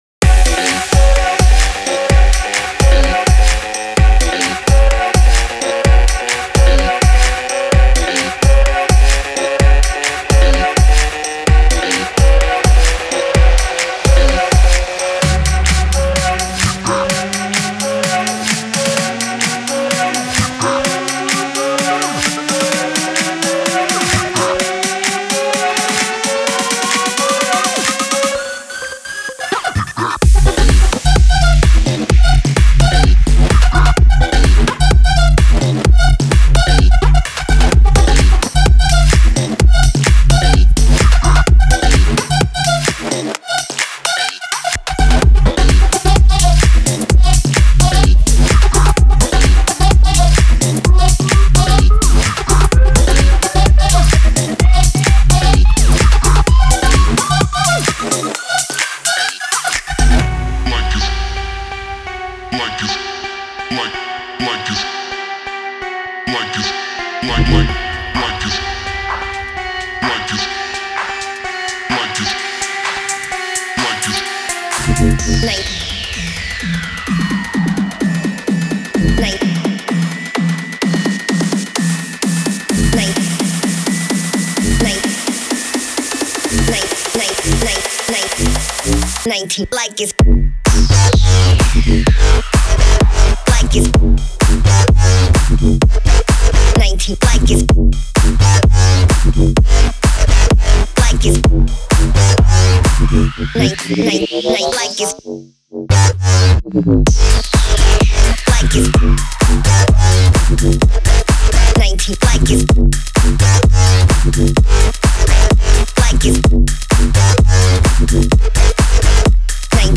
如果您正在寻找一些巨大的贝司线和惊人的铅环或胖子踢，您一定会喜欢这个系列的。